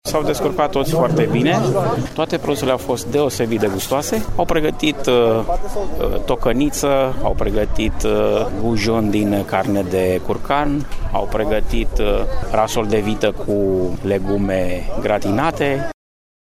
membru al juriului